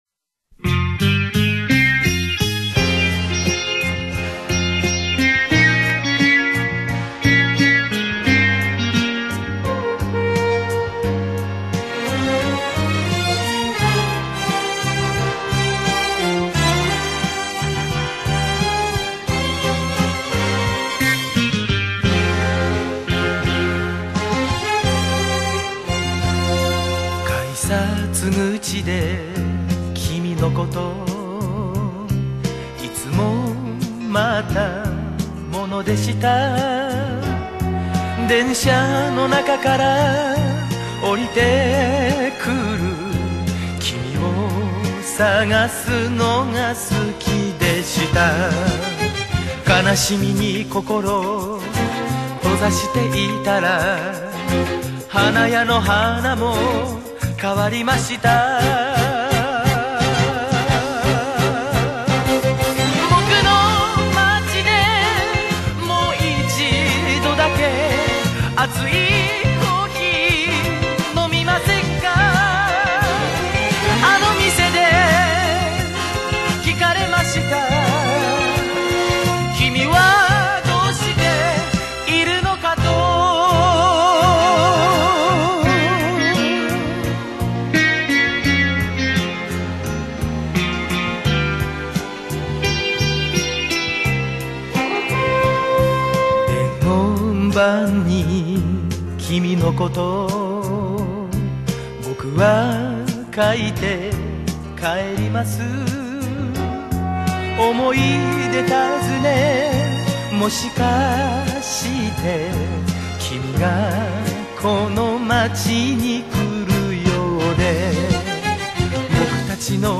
駅にまつわる歌謡曲